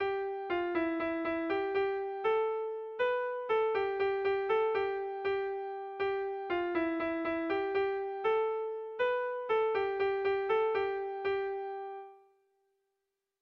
Pastoraletakoa
Lauko berdina, 2 puntuz eta 8 silabaz (hg) / Bi puntuko berdina, 16 silabaz (ip)
AA